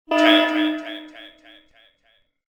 SelfDestructTen.wav